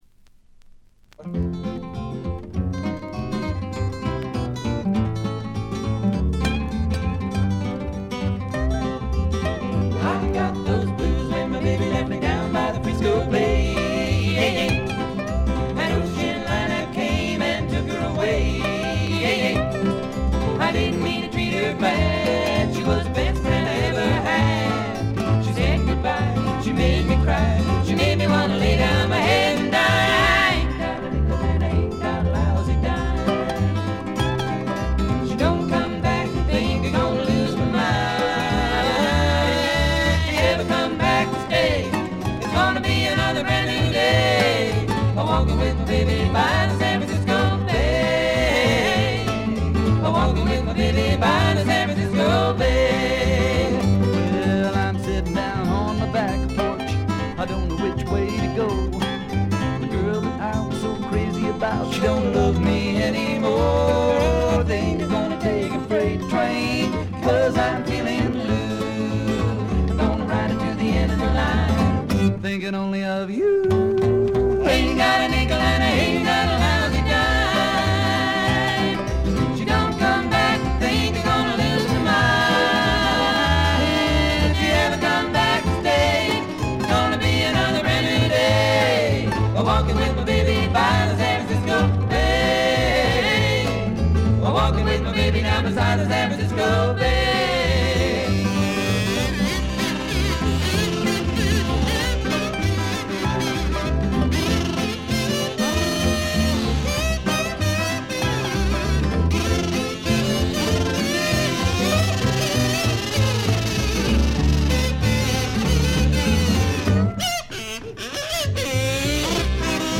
低いバックグラウンドノイズは常時出ていますが、特に目立つノイズはありません。
試聴曲は現品からの取り込み音源です。